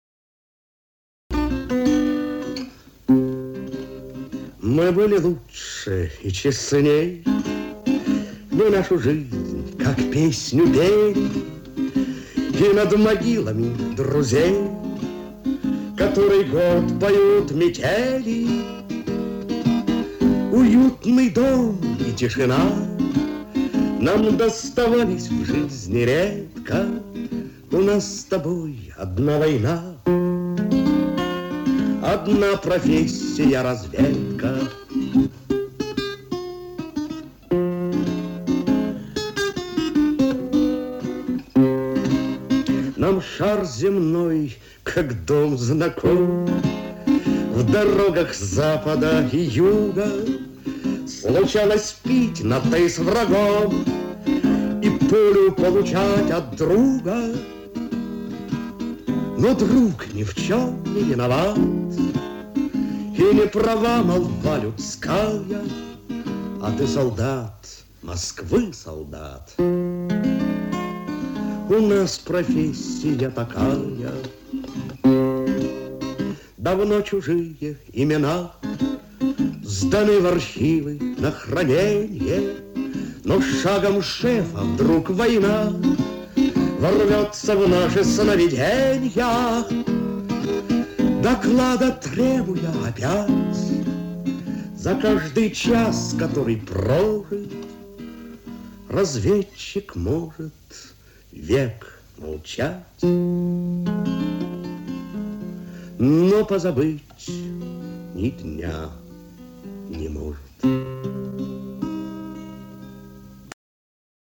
Запись с магнитной ленты 1969 года, поёт Вильям Генрихович Фишер (Рудольф Абель).
По-моему, это была радиопередача с его участием, где он не только пел, но и что-то рассказывал.